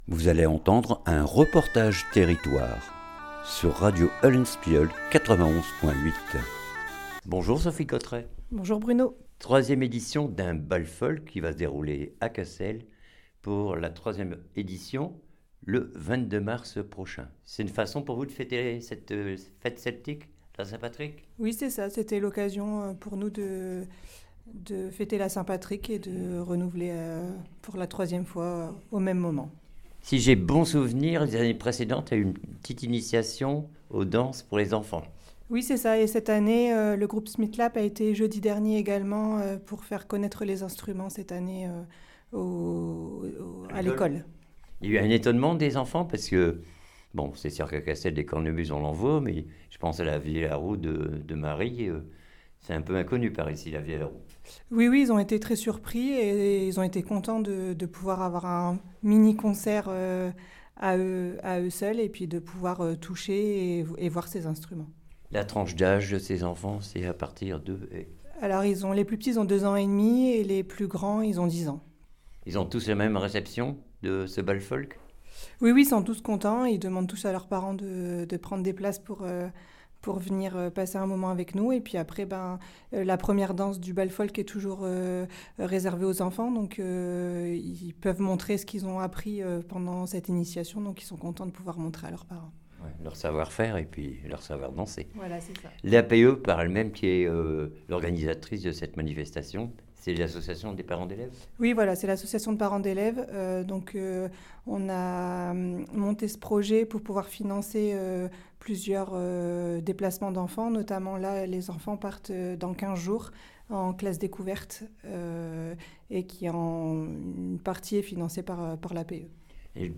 REPORTAGE TERRITOIRE BAL FOLK CASSEL 22 MARS CASSEL